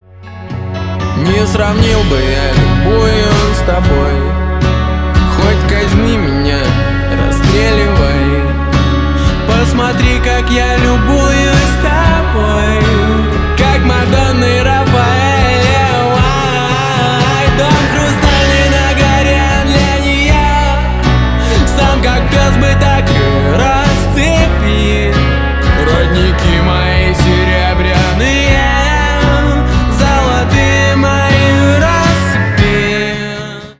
Stereo
Рэп и Хип Хоп